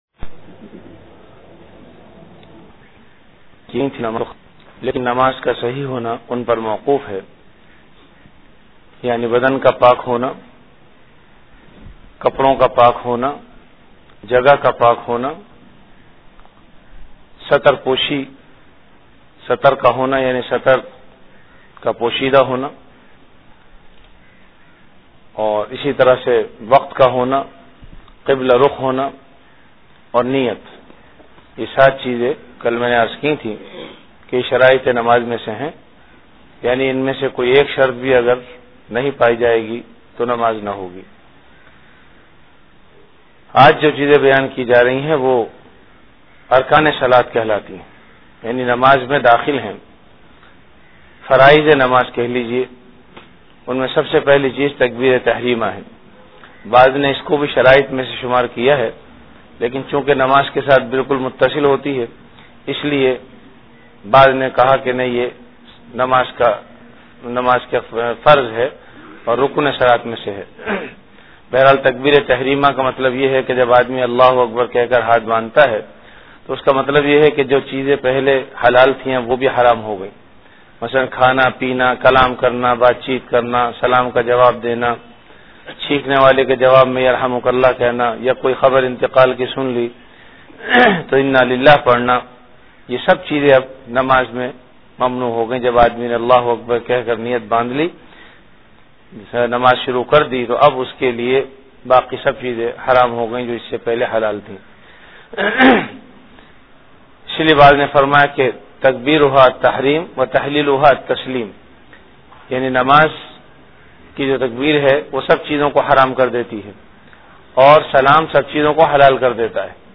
Ramadan - Dars-e-Hadees · Jamia Masjid Bait-ul-Mukkaram, Karachi